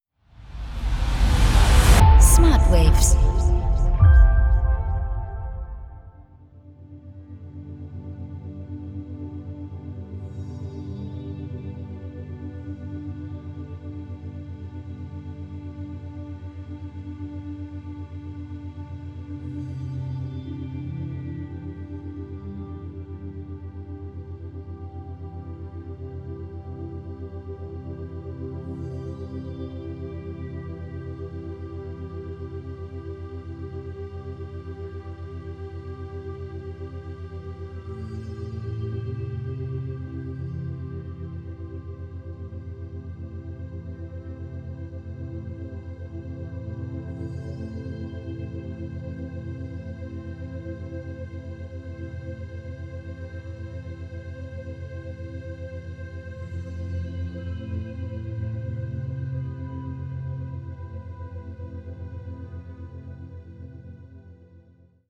entspannende und schwebende Umgebungsgeräusche
• Methode: Binaurale Beats